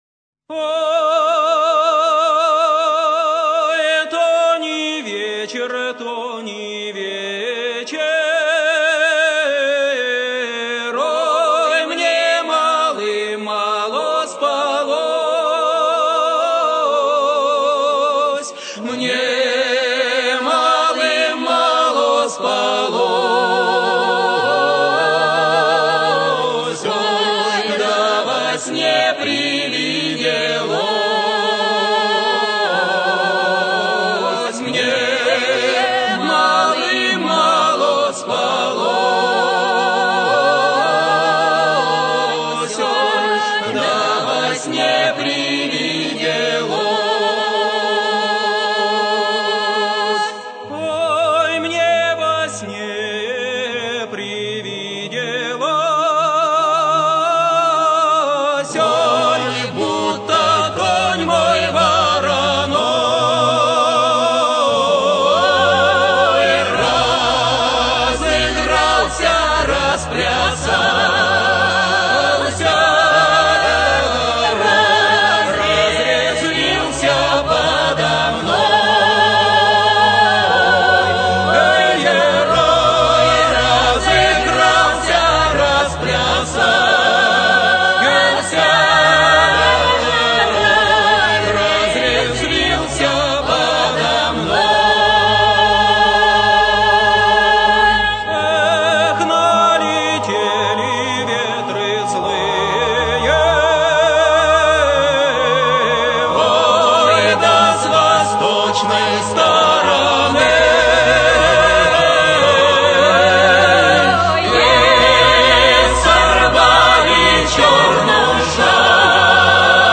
Русская народная песня "Ой,то не вечер"